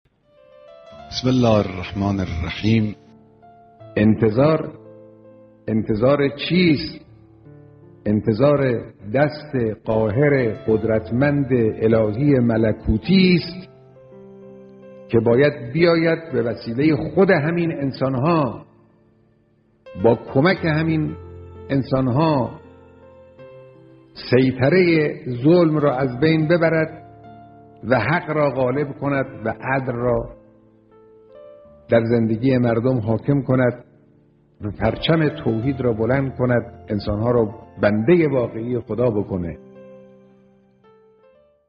گزیده ای از بیانات رهبر.mp3
• امام مهدی, صوت بیانات رهبر انقلاب, صوت بیانات مقام معظم رهبری, امام زمان